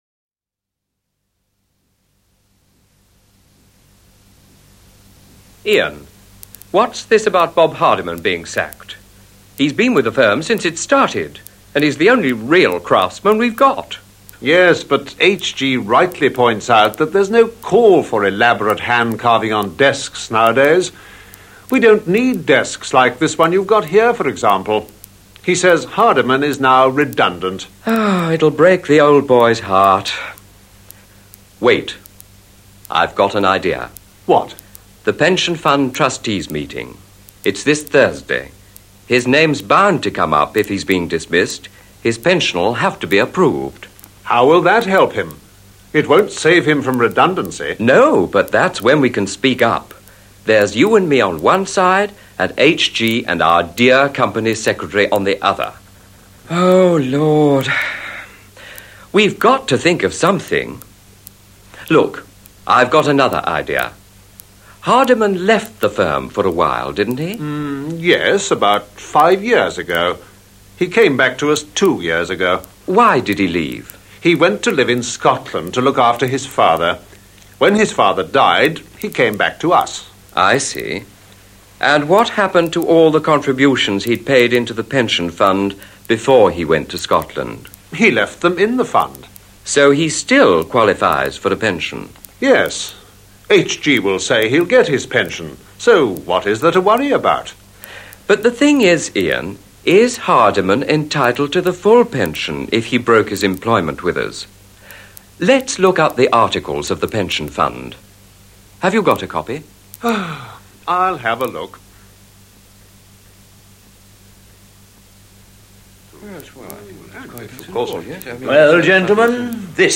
conversation12.mp3